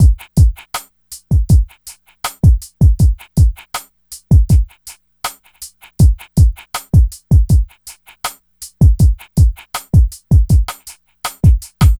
02C-DRM-80-R.wav